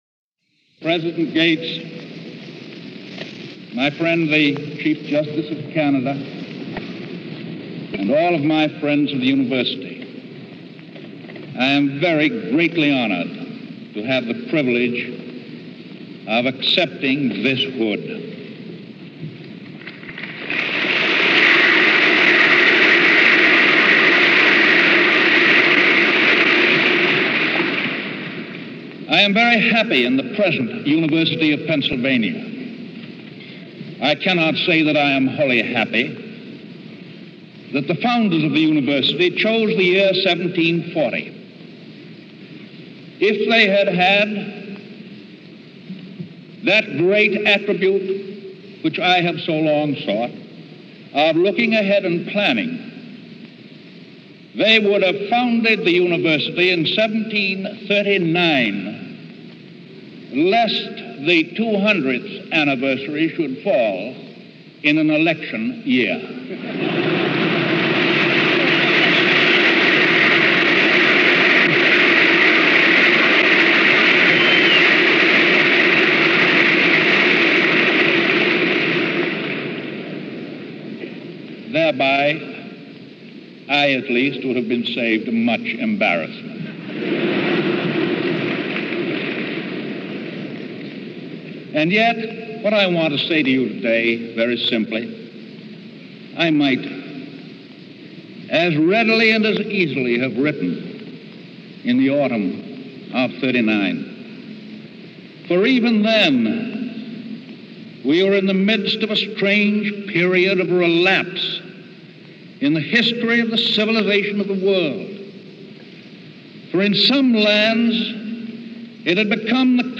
Celebrating the University’s Bicentennial, President Roosevelt addressed the students of the University of Pennsylvania, the first time a sitting President did so since 1909. To celebrate the occasion, FDR was presented with an honorary Doctor of Laws degree.